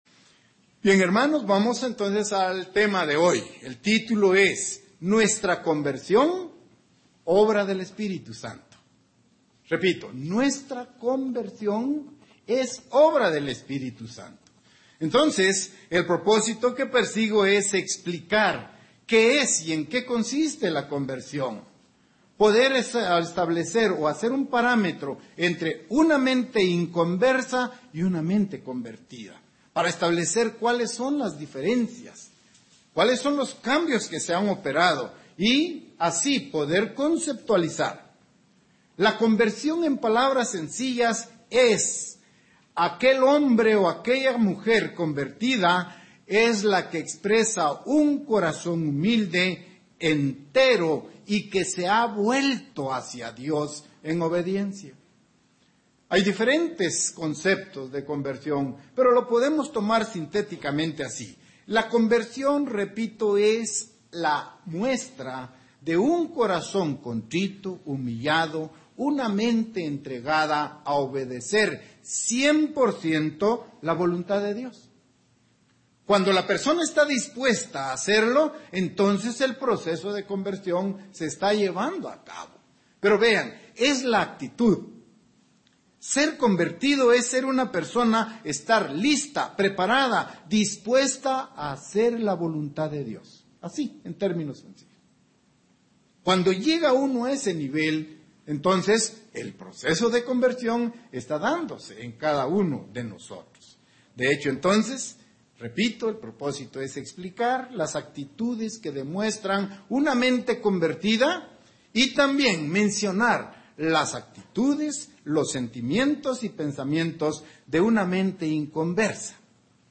Desarrollar un corazón humilde, sincero y abierto a la Palabra de Dios, no es una labor humana; necesitamos que El Eterno trabaje en nosotros a través de su Espíritu Santo. Mensaje entregado el 2 de junio de 2018.
Given in San Salvador